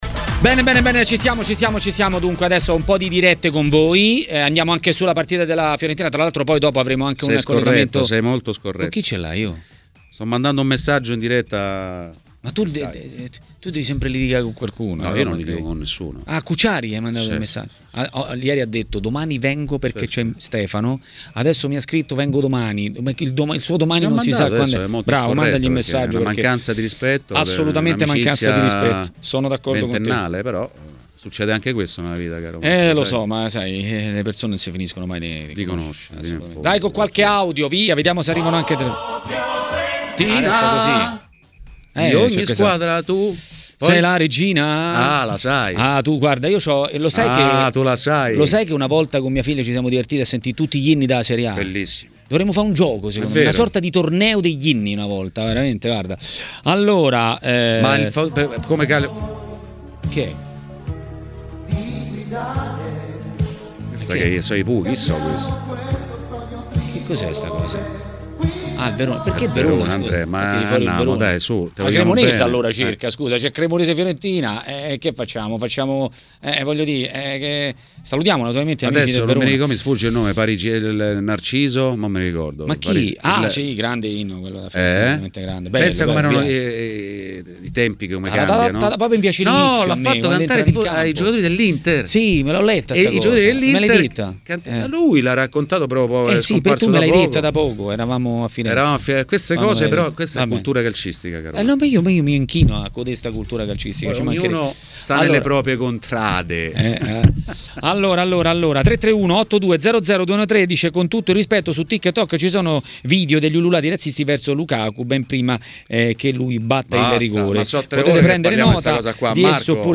giornalista ed ex calciatore, ha parlato a TMW Radio, durante Maracanà, della partita Juventus-Inter e non solo.